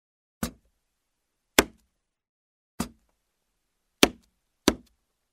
Звук печати
Звук обычной печати:
zvuk-obychnoj-pechati.mp3